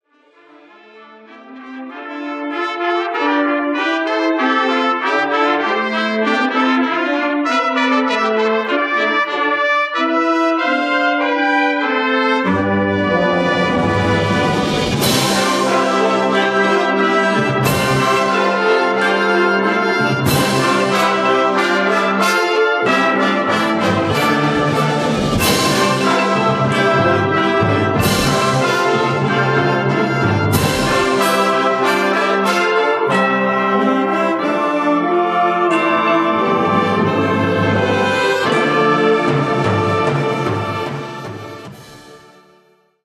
Swift Creations specializes in live and on location recordings in Pennsylvania.
The performance is captured utilizing digital audio hardware and software.
Concert Band 1